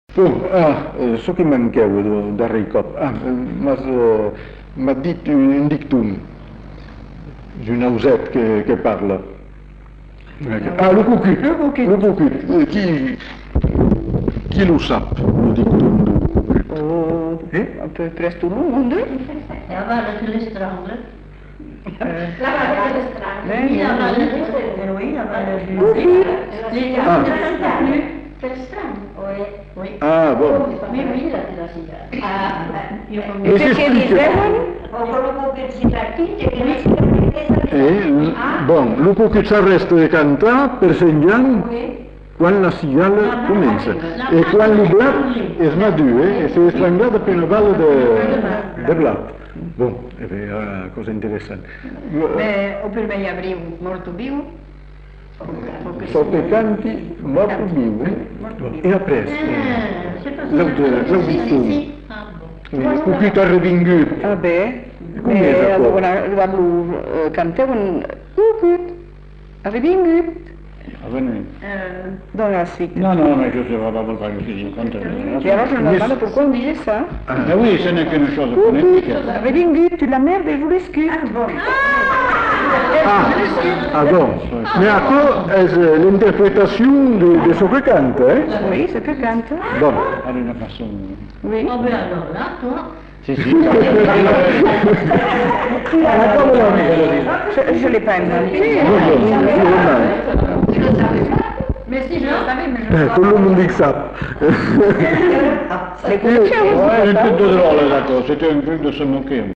Lieu : Uzeste
Genre : forme brève
Effectif : 1
Type de voix : voix de femme
Production du son : récité
Classification : mimologisme